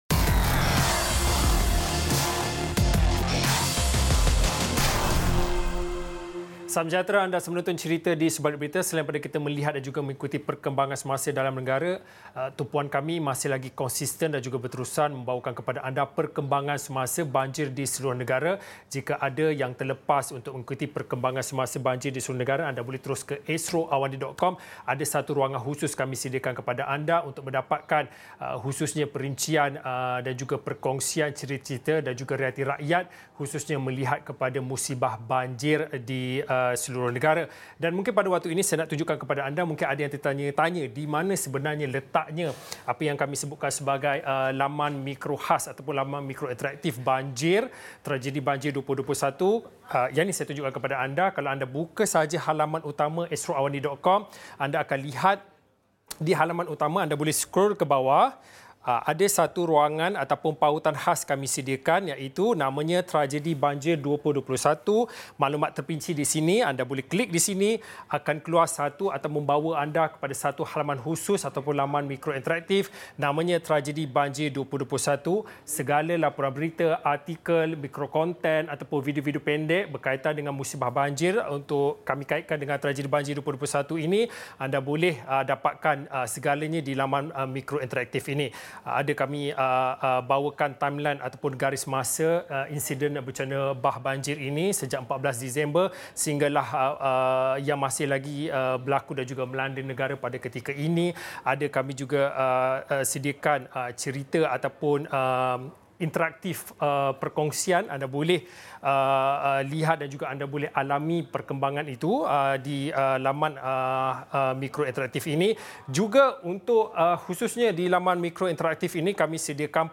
Wartawan Astro AWANI menceritakan kisah di sebalik sesuatu berita yang dilaporkan.